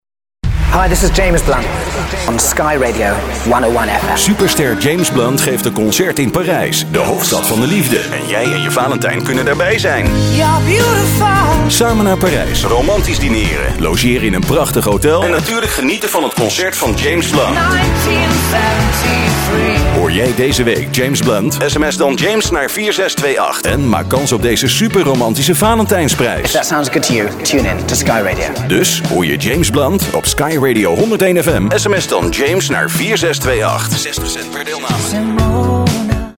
Kein Dialekt
Sprechprobe: eLearning (Muttersprache):